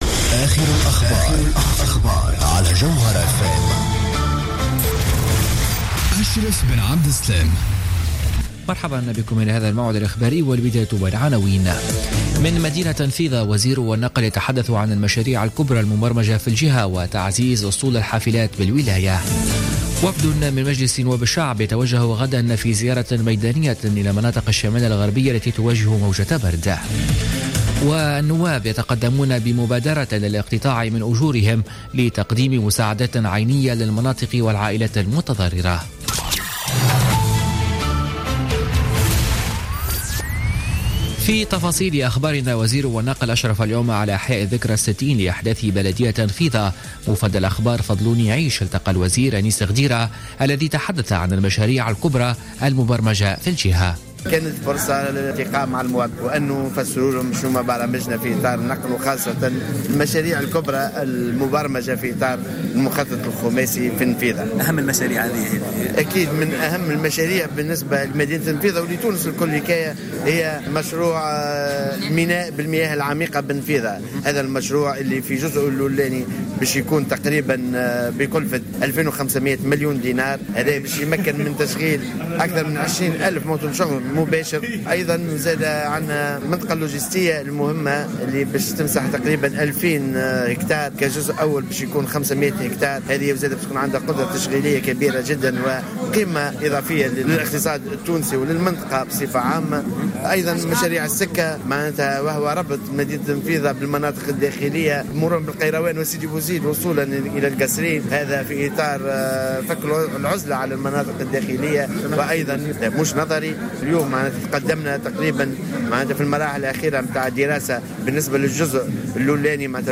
نشرة أخبار السابعة مساء ليوم الإربعاء 18 جانفي 2017